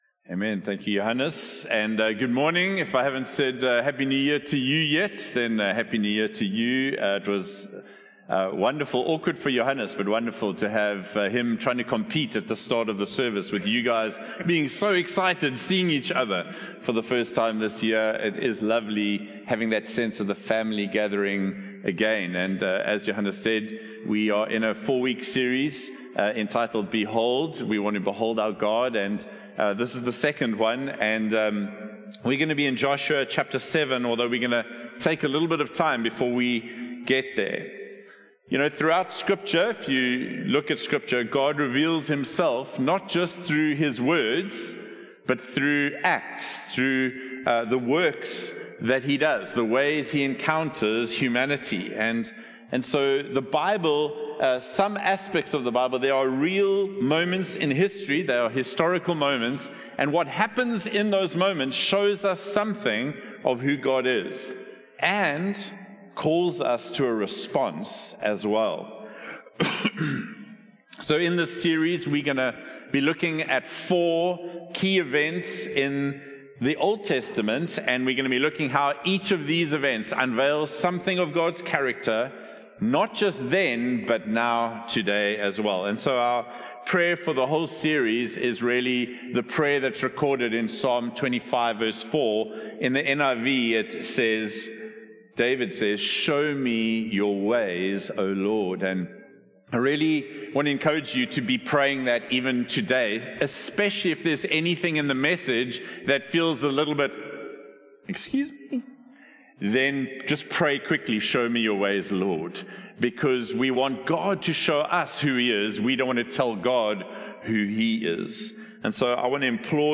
In this sermon from the "Behold" series